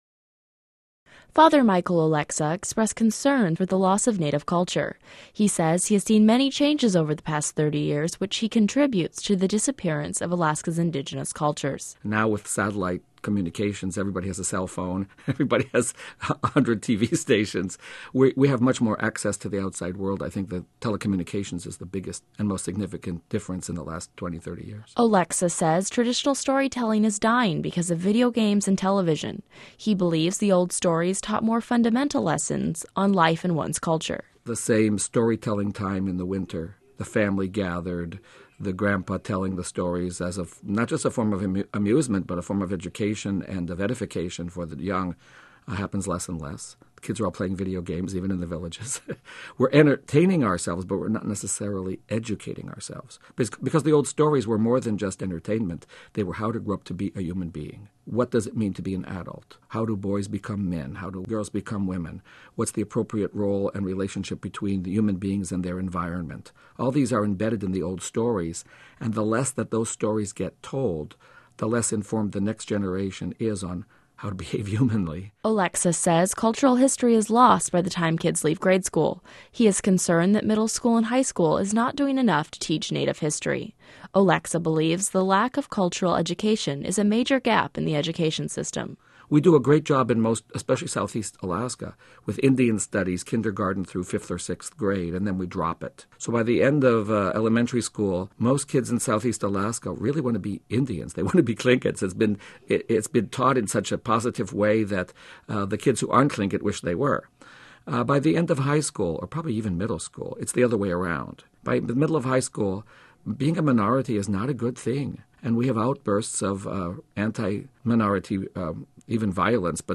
to discuss culture and it’s meanings at the Nolan Center’s Chautauqua lecture series (3/3).